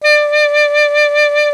55AF-SAX09-D.wav